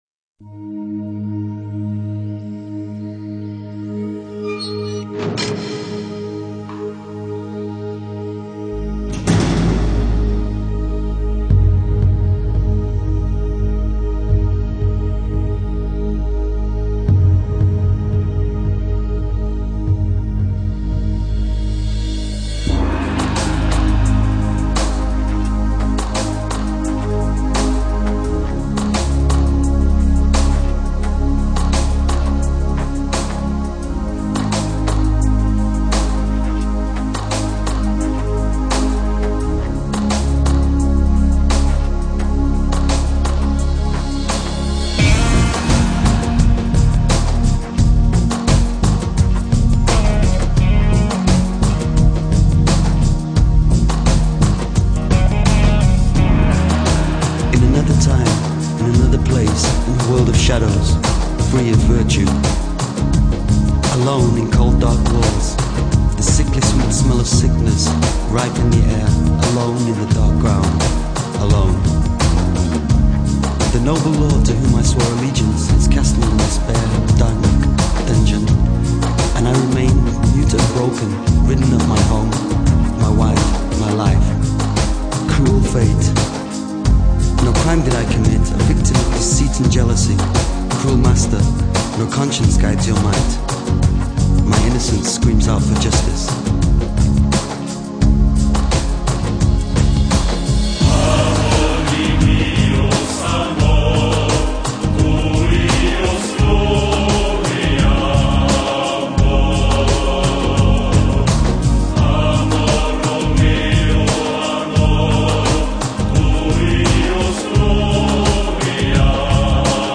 Мистическая музыка